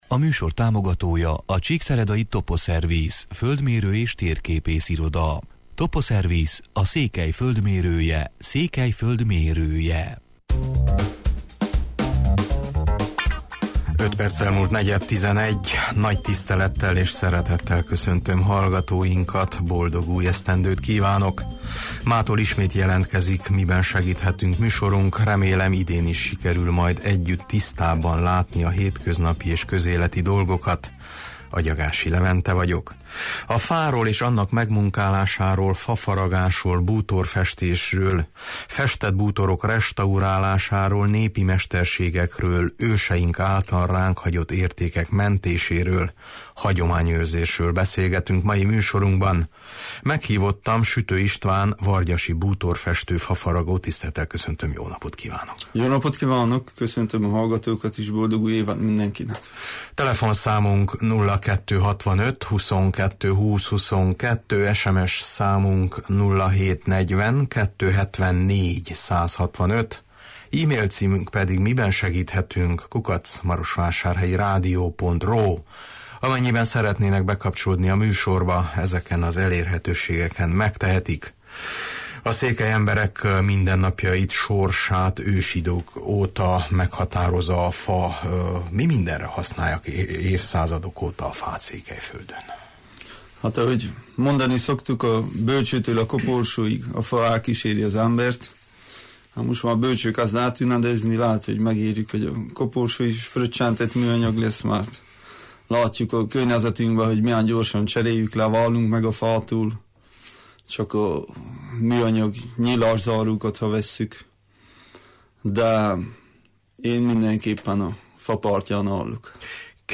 A fáról és annak megmunkálásáról, fafaragásról, bútorfestésről, festett bútorok restaurálásáról, népi mesterségekről, őseink által ránk hagyott értékek mentéséről, hagyományőrzésről beszélgetünk mai műsorunkban.